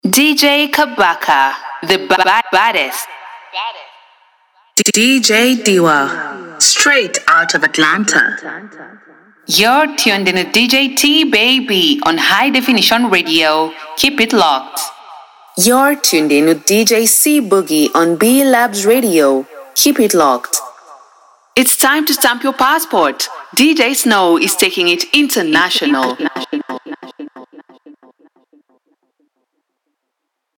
African Female DJ Drops
Stand out with our personalized African female DJ drops, professionally recorded by a Kenyan voice artist. Authentic accent.
2026-African-drops.mp3